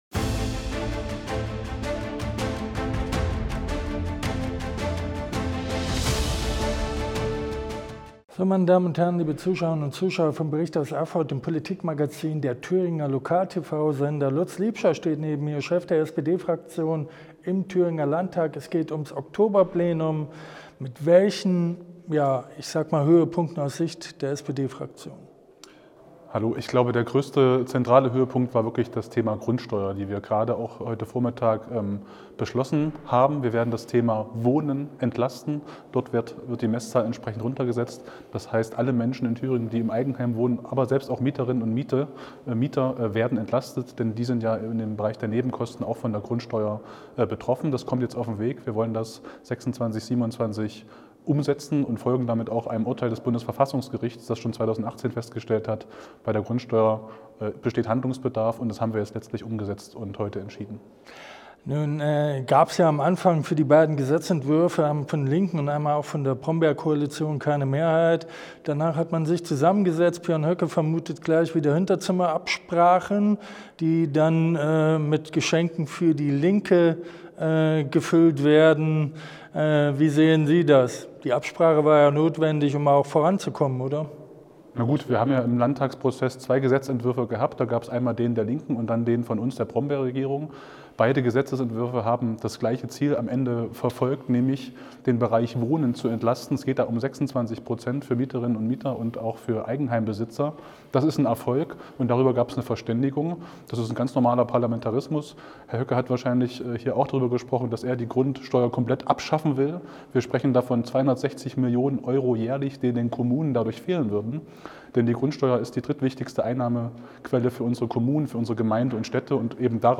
durch Erfurts vorweihnachtliche Innenstadt gegangen.